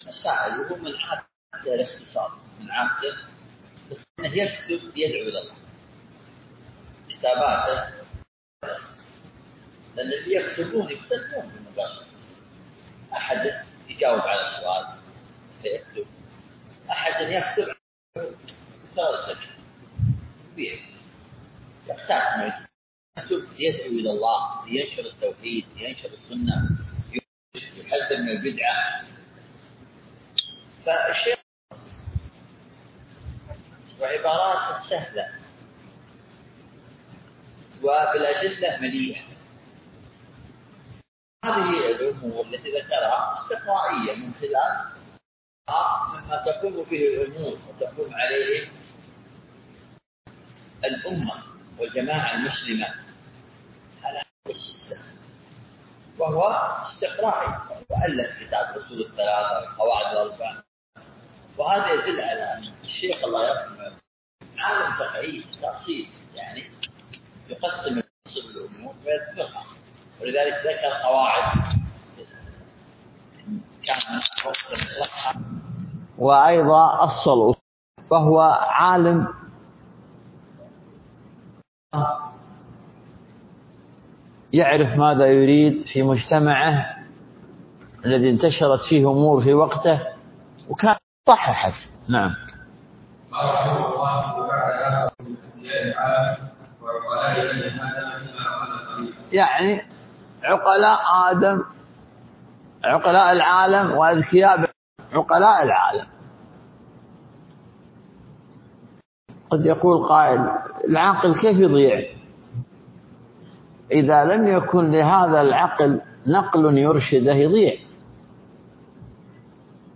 الدرس 1